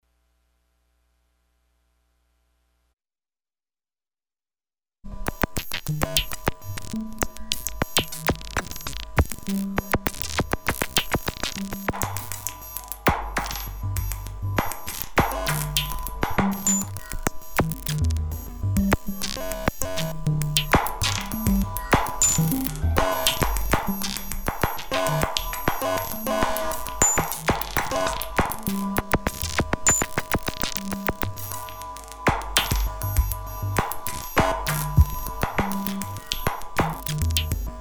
no eq, compression, nothing.
i just made sure it was clean and i took an empty 1/2" reel box and leaned it up against the plate for a little dampening and later loosened it just a little bit for the synth and the 808 samples.
each example starts with a little piece of the static that the amp alone generates [ just so you are aware of it ] then its muted and unmuted during the course of the example.
percussion 2.mp3